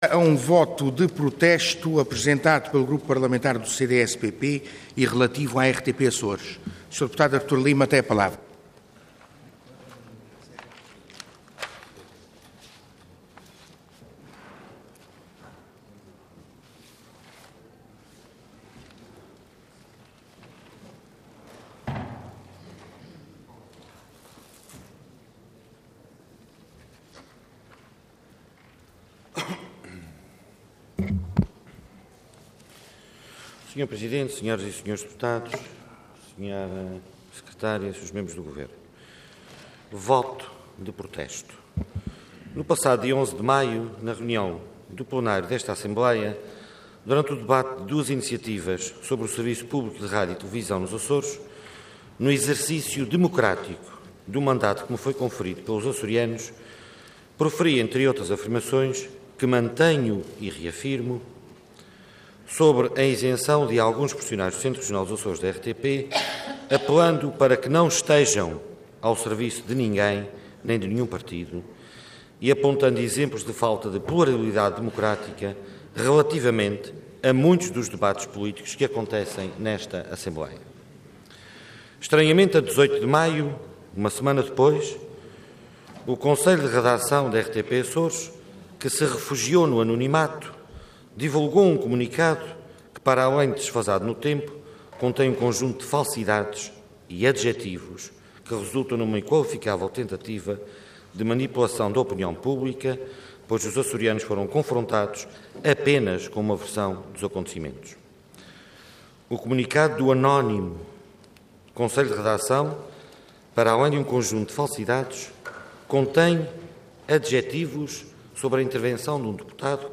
Intervenção Voto de Protesto Orador Artur Lima Cargo Deputado Entidade CDS-PP